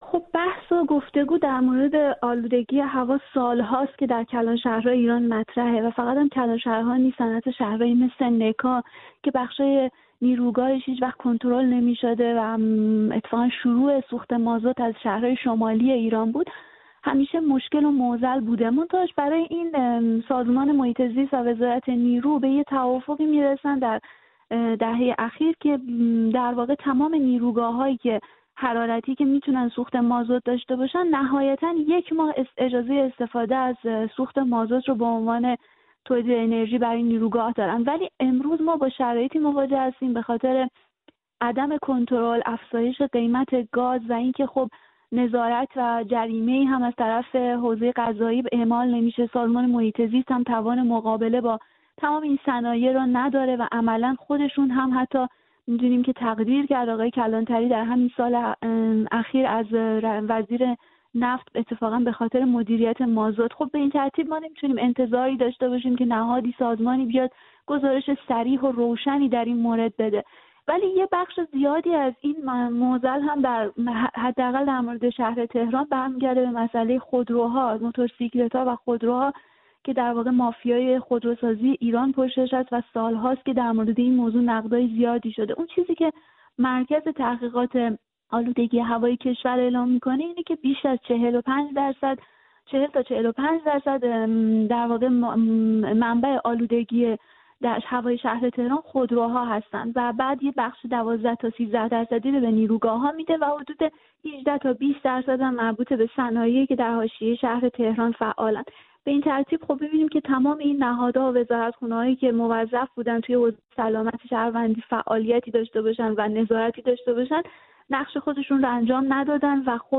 در حالی که کیفیت هوا وموضوع افزایش آلاینده ها در کلانشهرهای ایران همچنان مورد نگرانی کارشناسان است خبرها از ایران از اظهار نظرهای ضد و نقیض از سوی مقامات در جمهوری اسلامی حکایت دارد. گفت‌وگوی